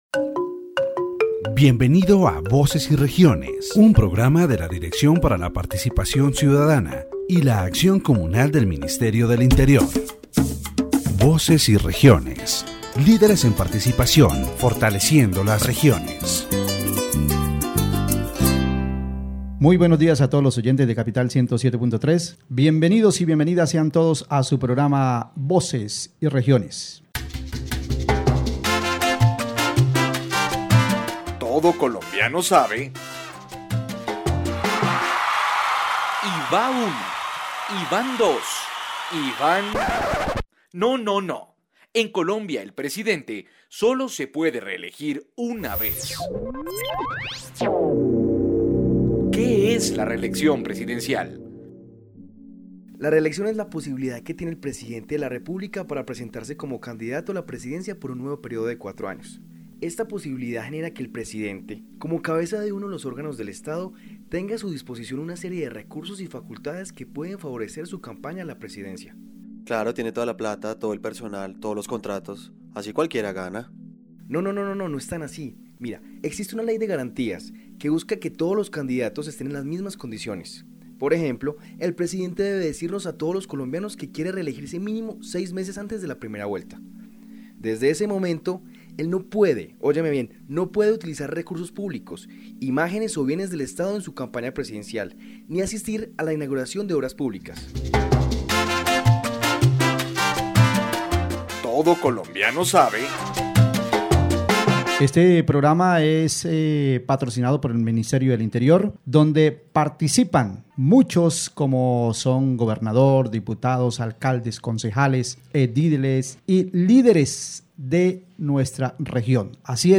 a visually impaired guest